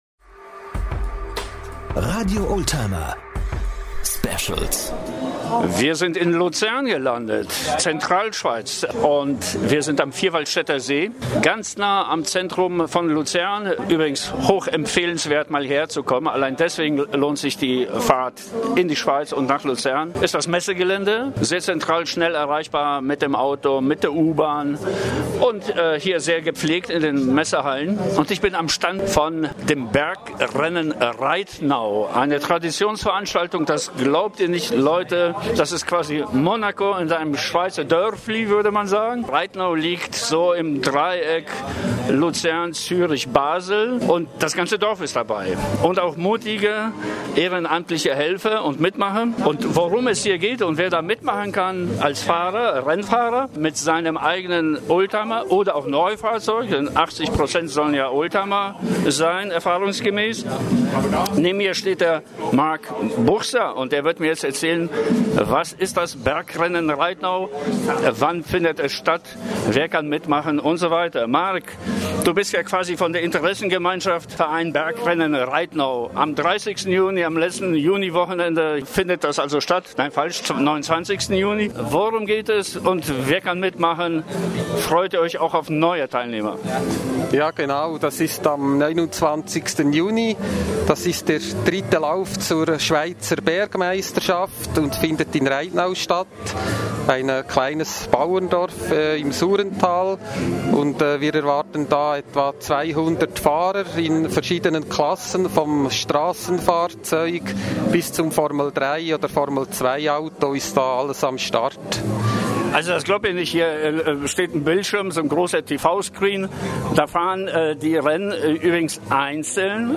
Auf der Messe Swiss Classic World in Luzern führten wir das Interview mit dem Team des Rennvereins Reitnau.